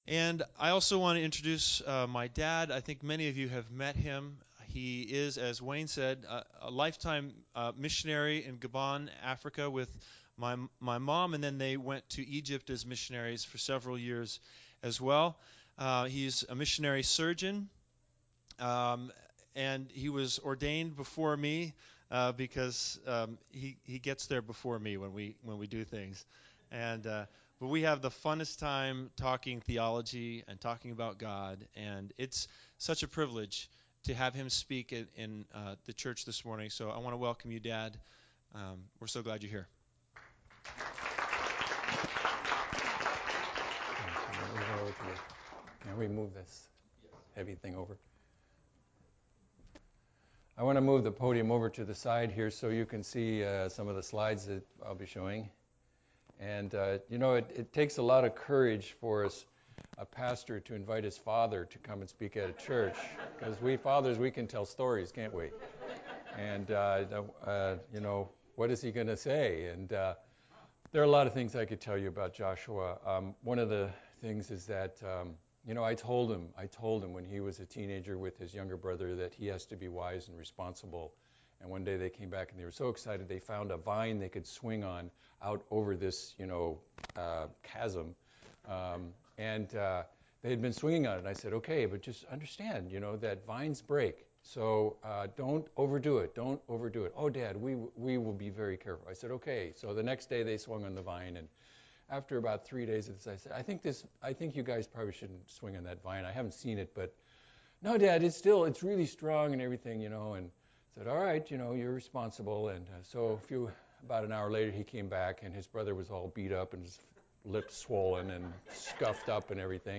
Special Sermon Passage